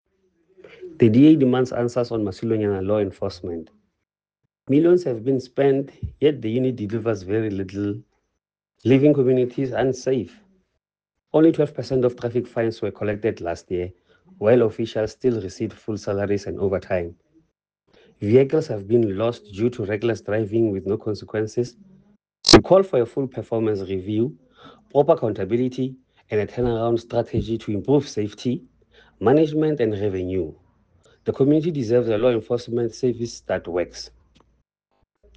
English and Sesotho soundbites by Cllr Ernst Putsoenyane and